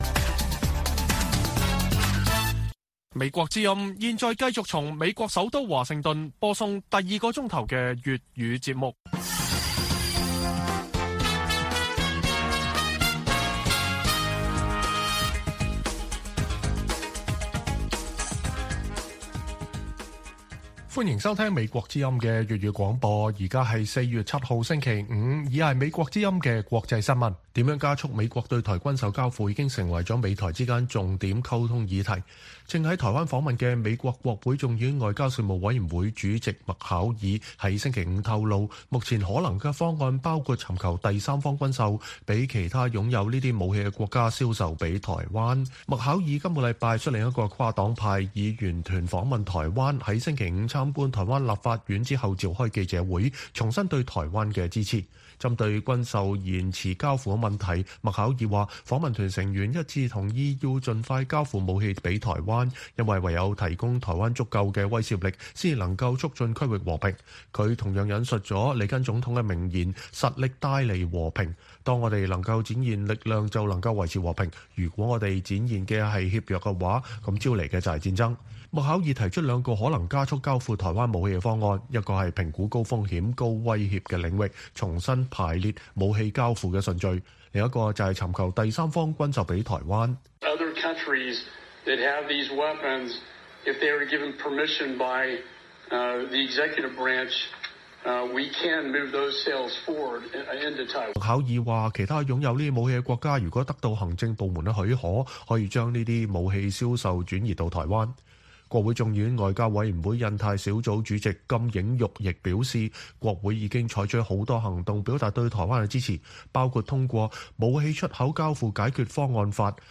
北京時間每晚10－11點 (1400-1500 UTC)粵語廣播節目。內容包括國際新聞、時事經緯和社論。